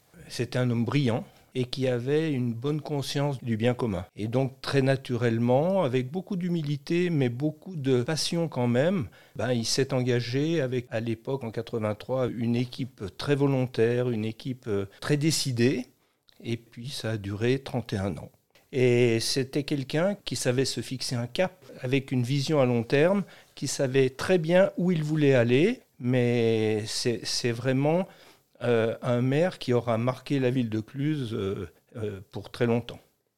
Jean-Claude Tavernier, son adjoint pendant de nombreuses années, lui rend hommage :